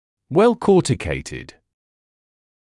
[wel’kɔːtɪkeɪtɪd][уэл’коːтикэйтид]имеющий хороший кортикальный слой